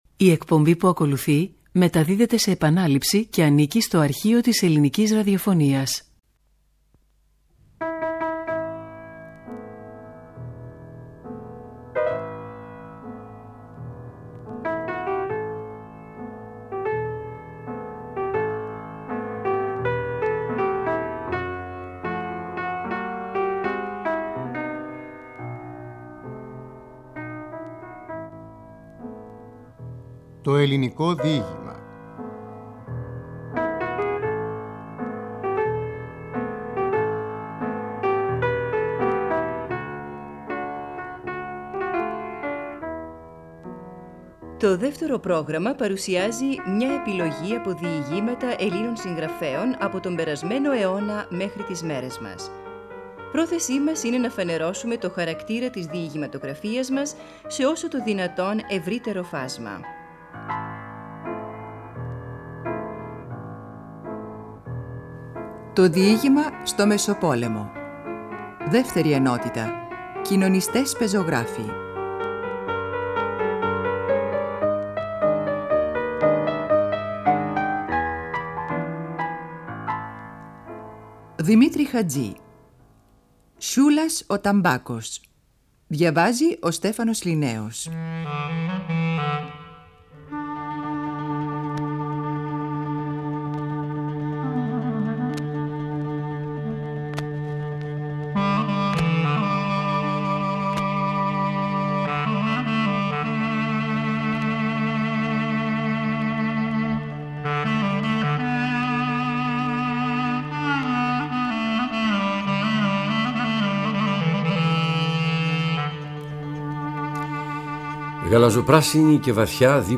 Από τη σειρά εκπομπών με τίτλο «Το Ελληνικό Διήγημα» στο Δεύτερο Πρόγραμμα.
Ο Στέφανος Ληναίος διαβάζει το διήγημα «Σιούλας ο Ταμπάκος» .